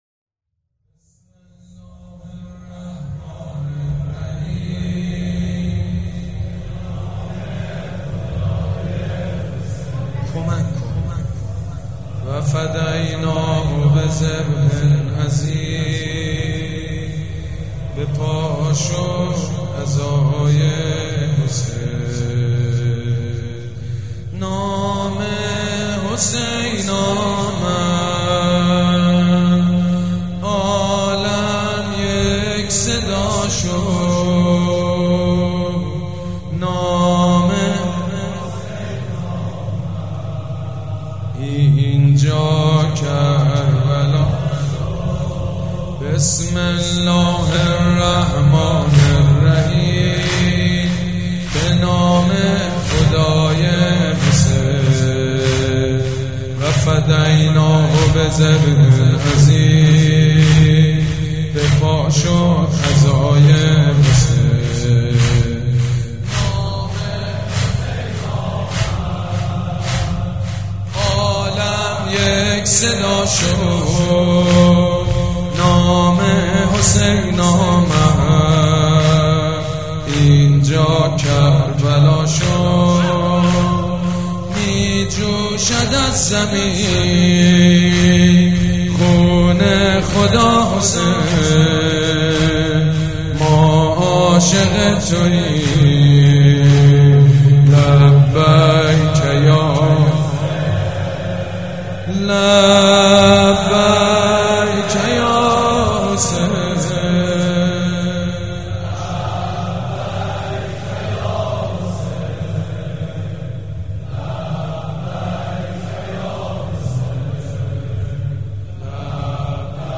مداح
مراسم عزاداری شب هشتم